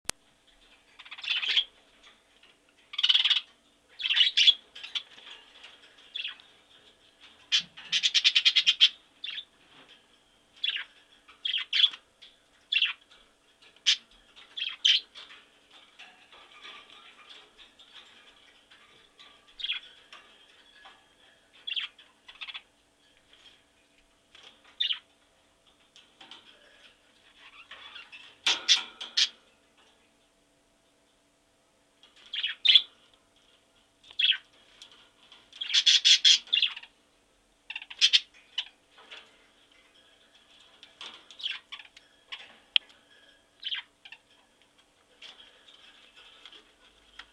На этой странице собраны звуки, которые издают попугаи: от веселого чириканья до мелодичного пения.
Звуки волнистого попугайчика